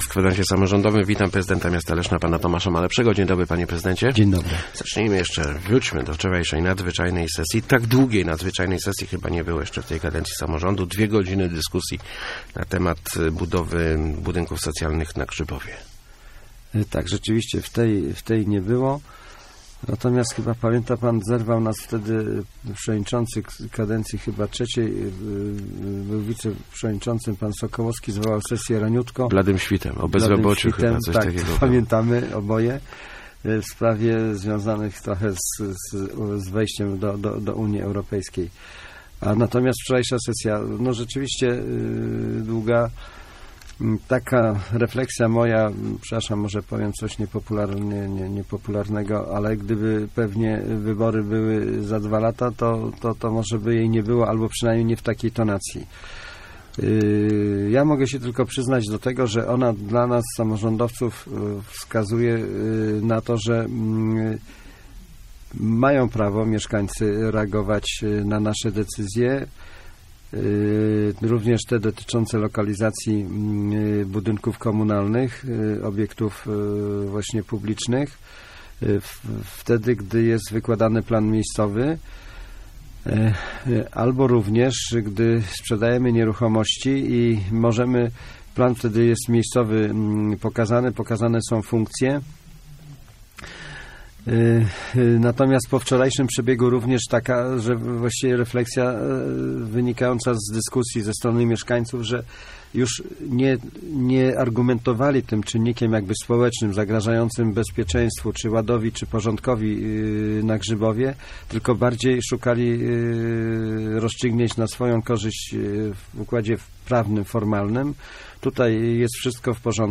Gościem Kwadransa był prezydent Tomasz Malepszy ...